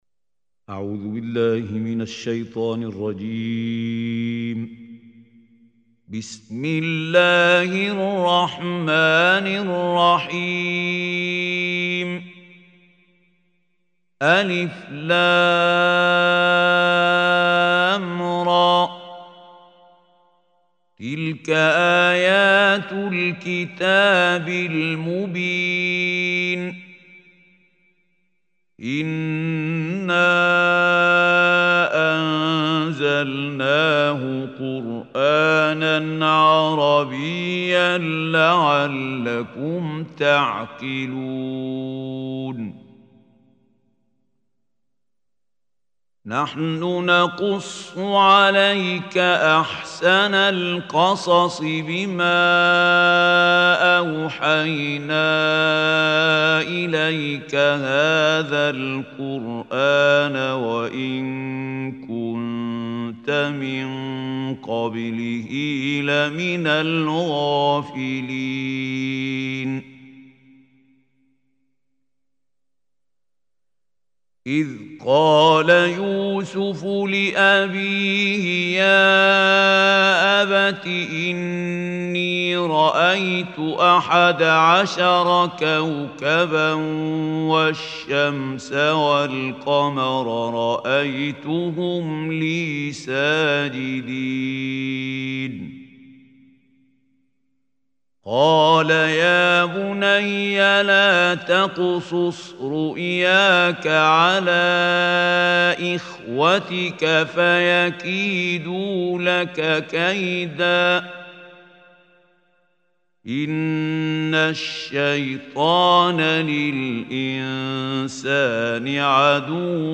Surah Yusuf Recitation by Mahmoud Khalil Hussary
Surah Yusuf is 12th chapter of Holy Quran. Listen online beautiful tilawat in the voice of Qari Mamoud Khalil Al Hussary.